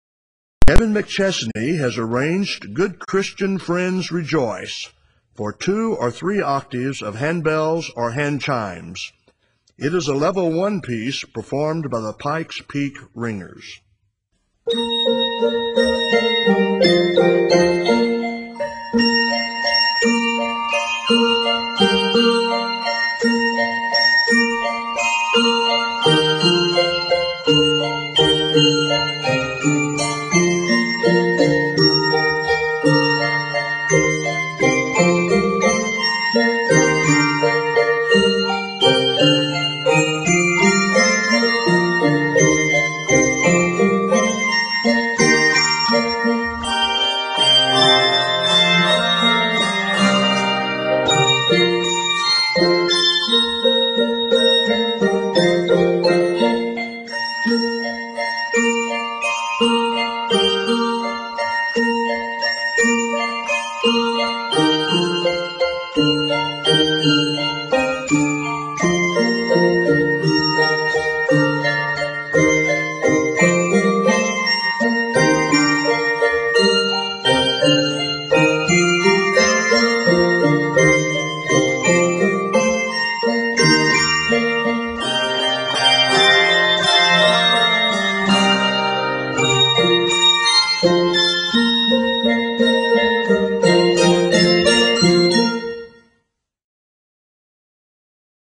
bouncy rendition of the carol